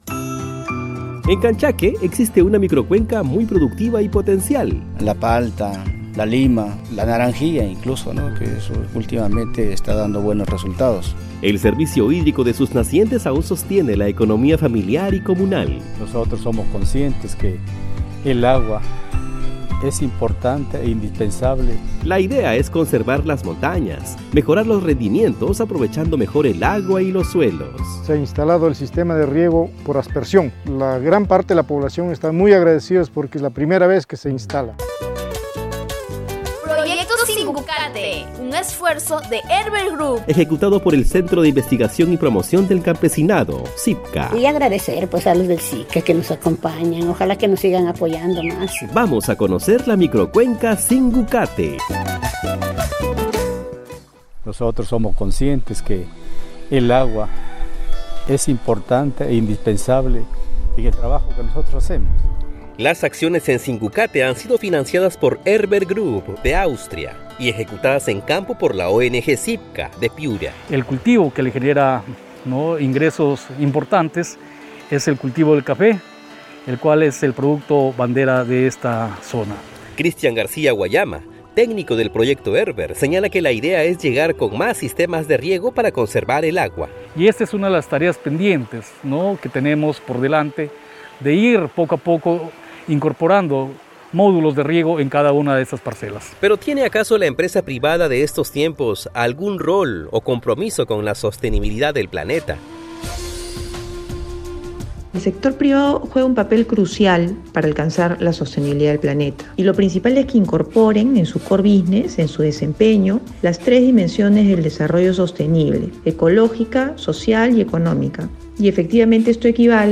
REPORTAJE-SINGUCATE-2da-parte.mp3